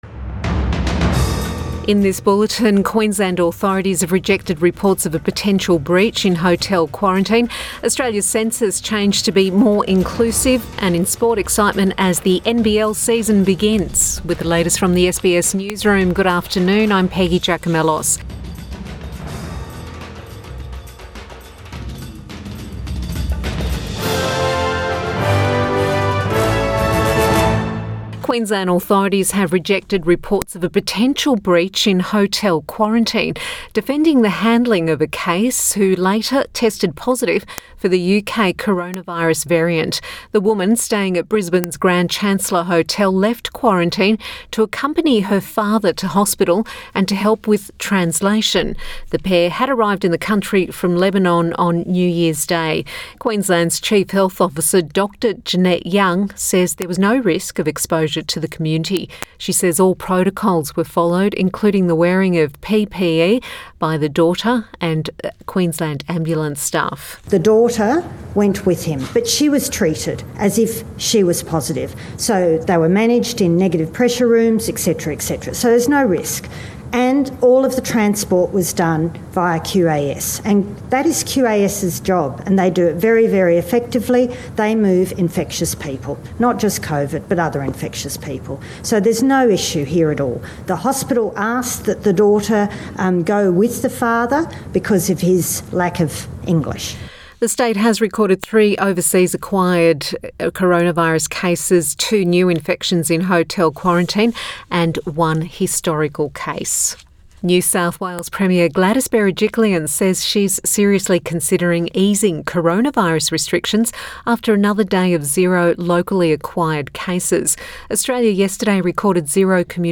Midday bulletin 15 January 2021